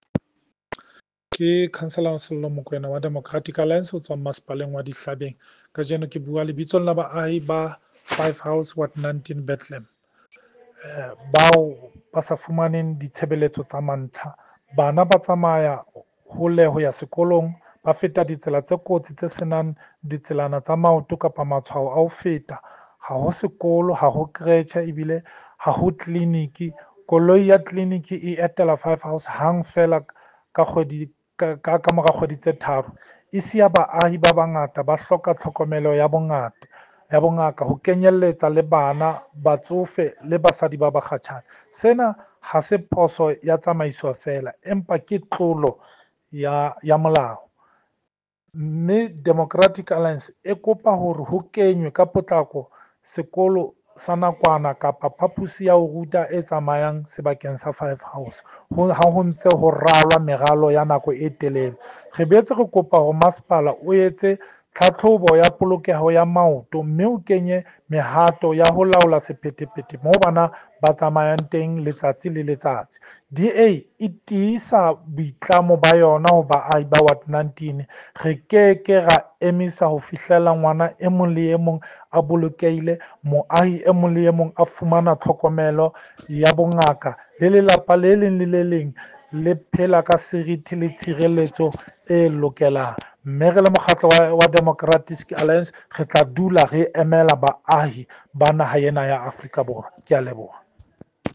Sesotho soundbites by Cllr Sello Makoena.